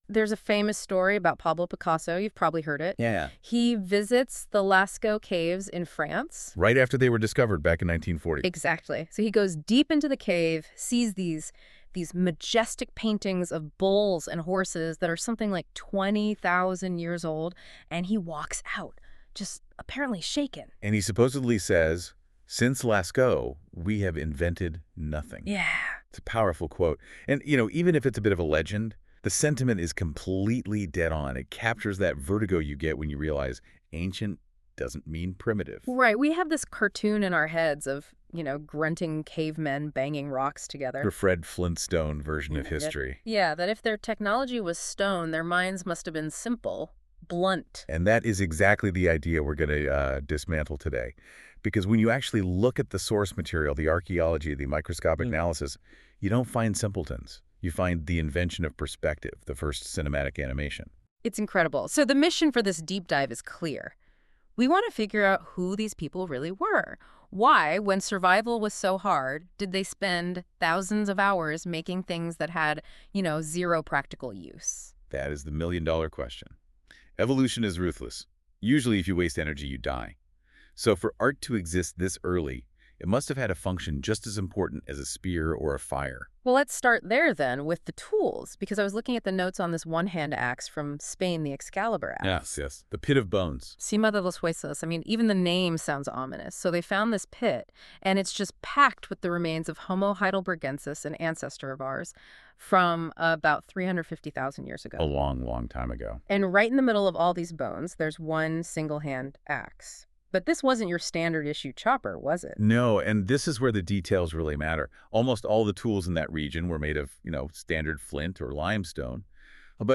A discussion on The Earliest Art (created by NotebookLM from my notes):